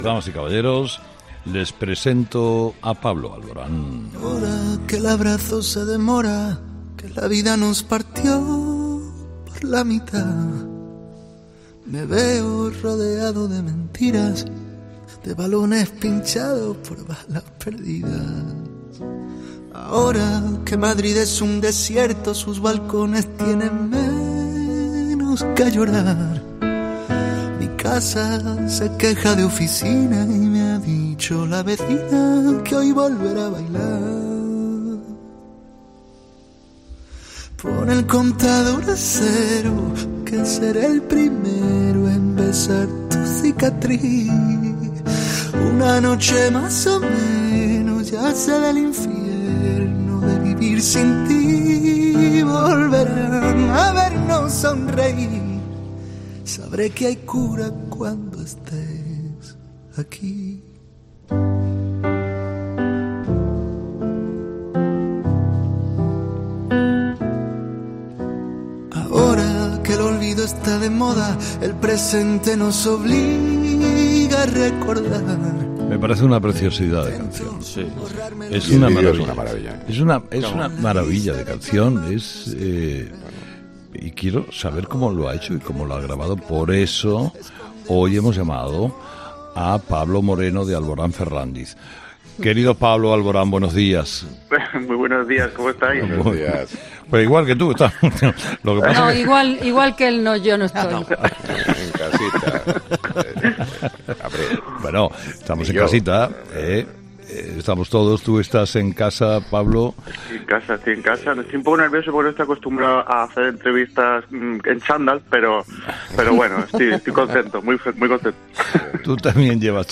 Pablo Alborán en 'Radio Carlitos' en "Herrera en COPE"